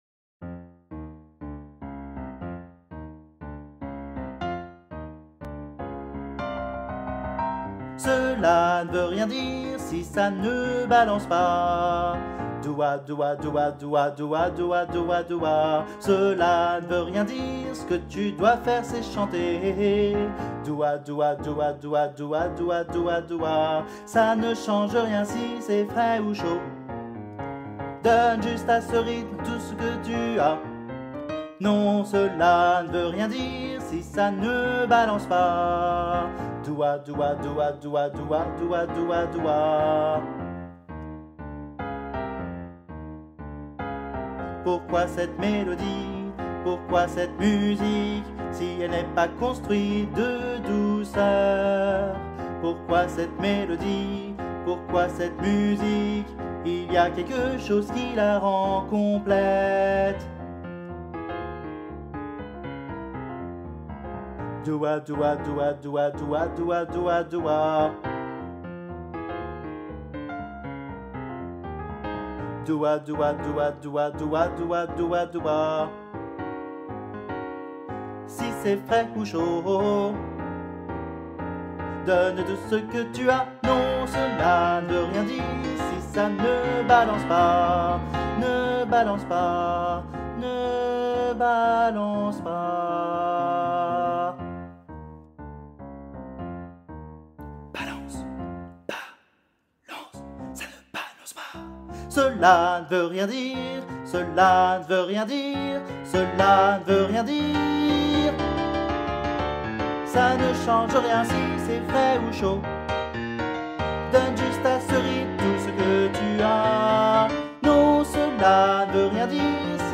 MP3 versions chantées
Alto et Basse